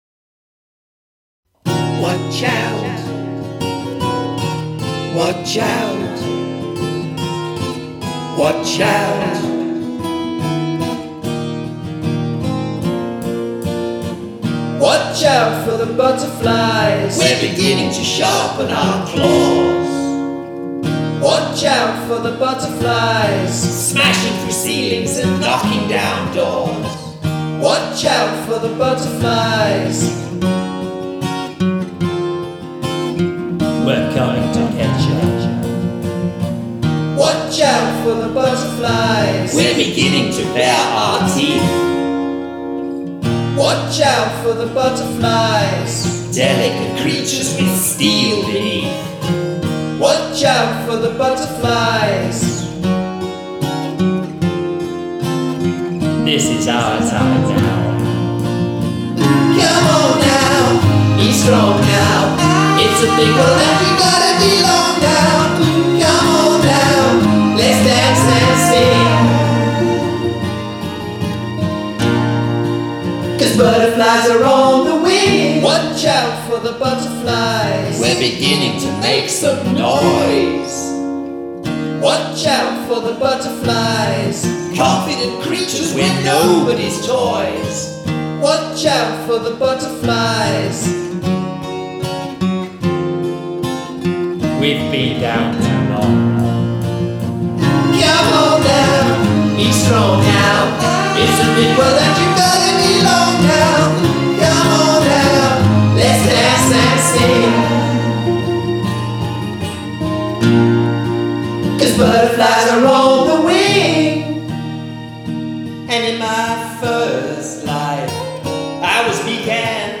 Most of the music was performed on my trusty acoustic guitar, with a little bit of accompaniment courtesy of GarageBand’s array of instruments.